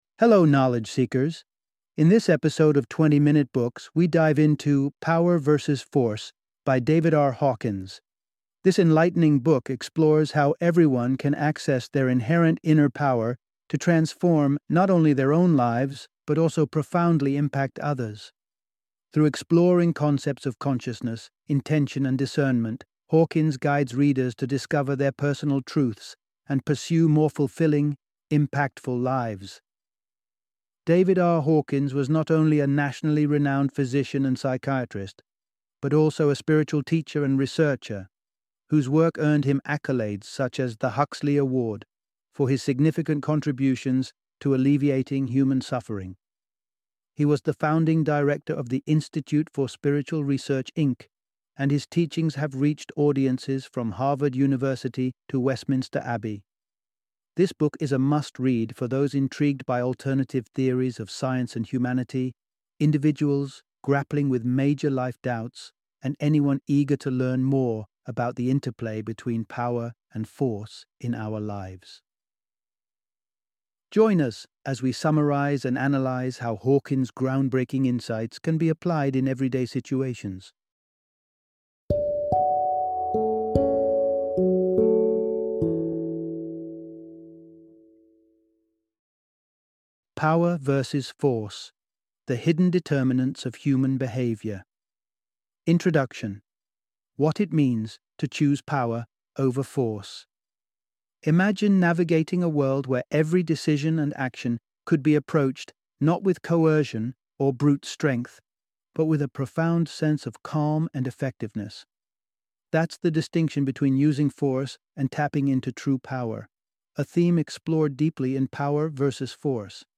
Power vs. Force - Audiobook Summary